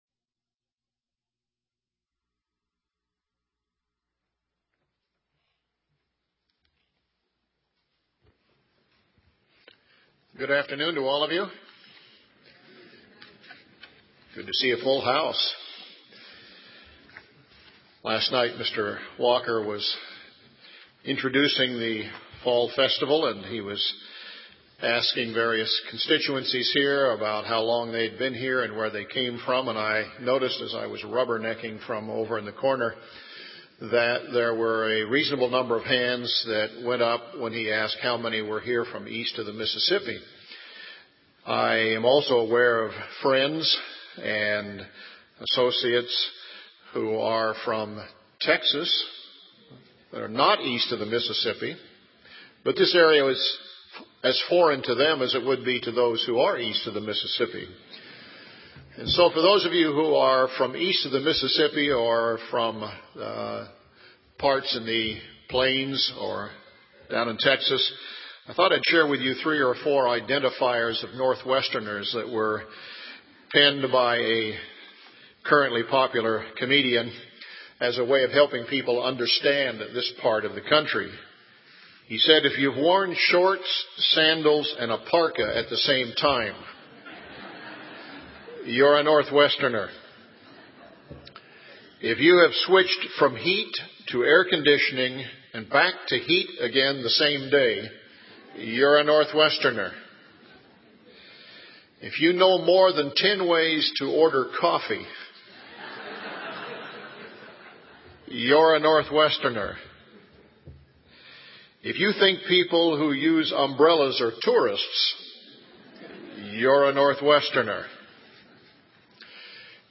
This sermon was given at the Bend, Oregon 2010 Feast site.